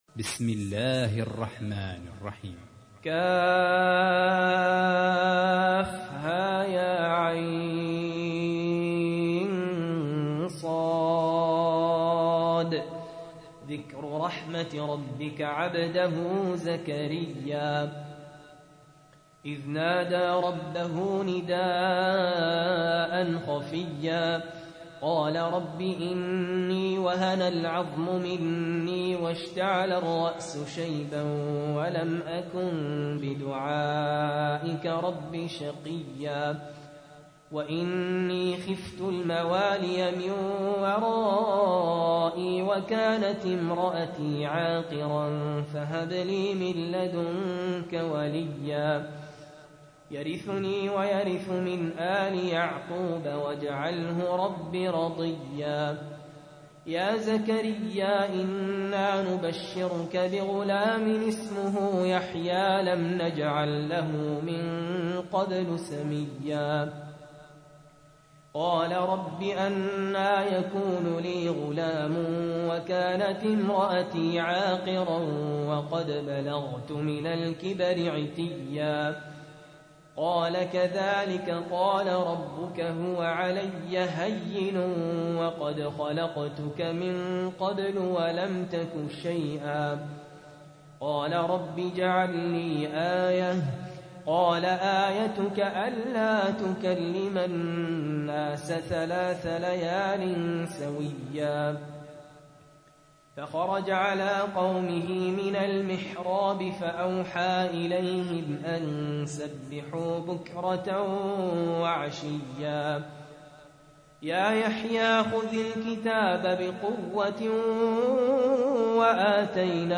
تحميل : 19. سورة مريم / القارئ سهل ياسين / القرآن الكريم / موقع يا حسين